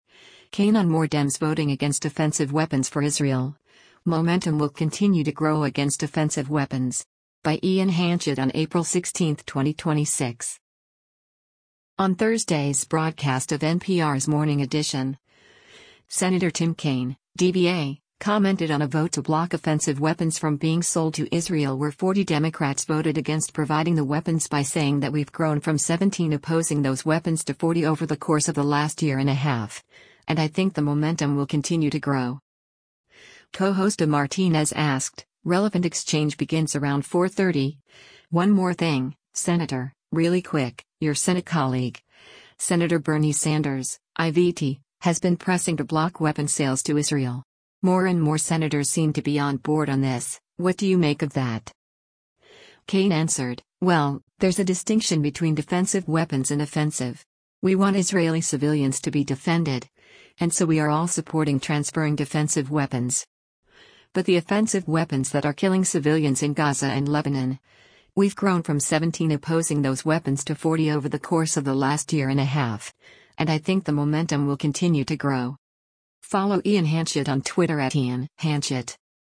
On Thursday’s broadcast of NPR’s “Morning Edition,” Sen. Tim Kaine (D-VA) commented on a vote to block offensive weapons from being sold to Israel where 40 Democrats voted against providing the weapons by saying that “we’ve grown from 17 opposing those weapons to 40 over the course of the last year and a half, and I think the momentum will continue to grow.”